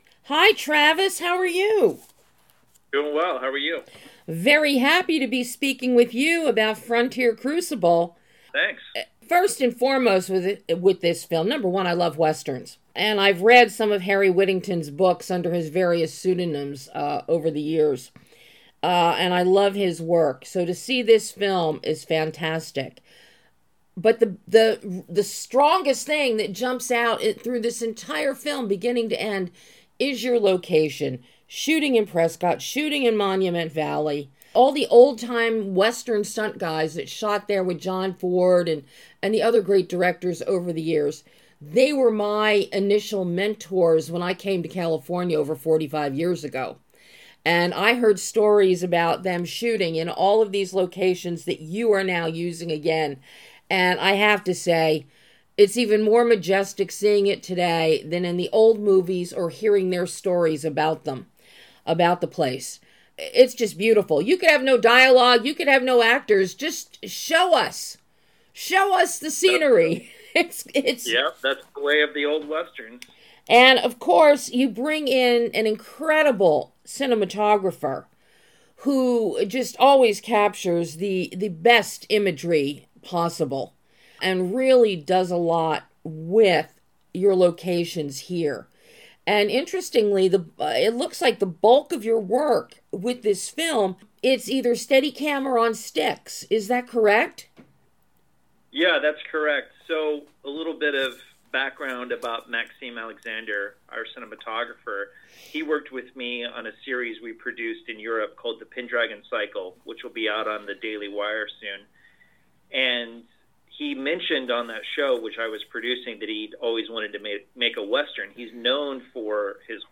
FRONTIER CRUCIBLE - Exclusive Interview